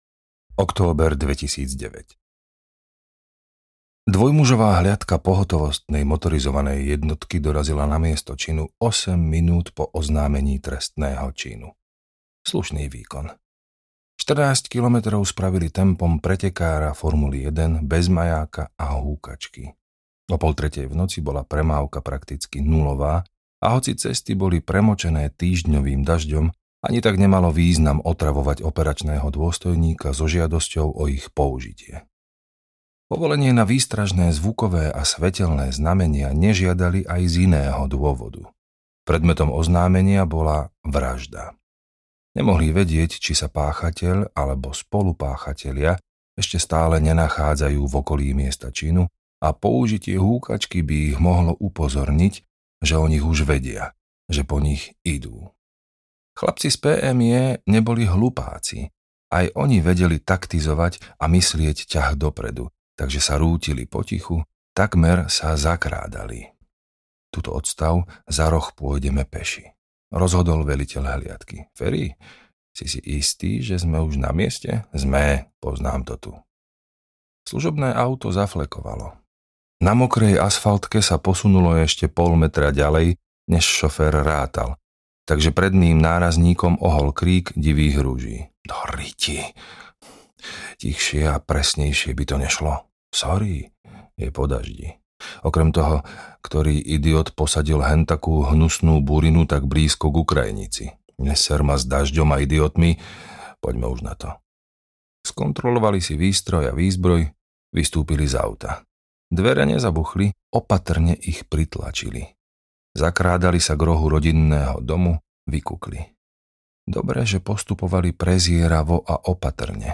Klbko zmijí audiokniha
Ukázka z knihy
klbko-zmiji-audiokniha